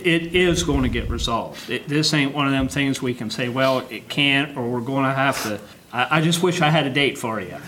Commissioner Creade Brodie reassured those attending the meeting that action will be taken to get the ambulance reinstated…